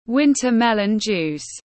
Nước ép bí đao tiếng anh gọi là winter melon juice, phiên âm tiếng anh đọc là /’wintə ‘melən ˌdʒuːs/